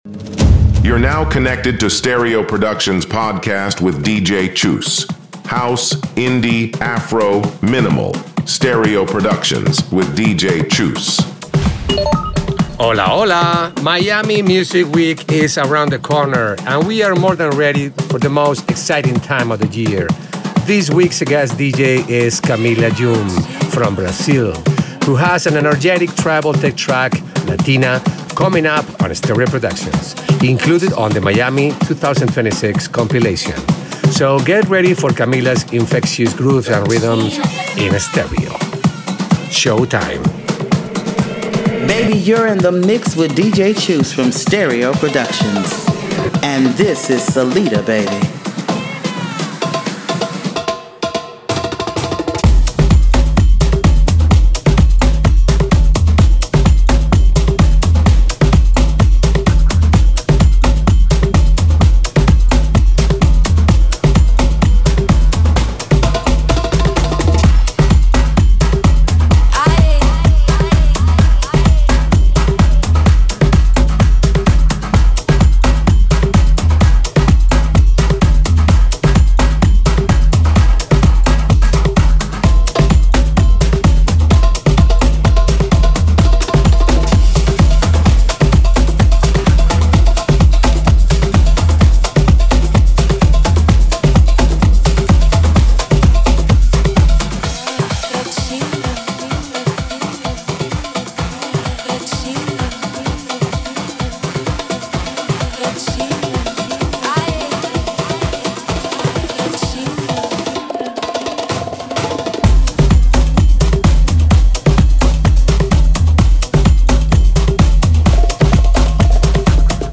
a powerful dose of Latin Tech energy
a vibrant and driving mix